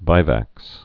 (vīvăks)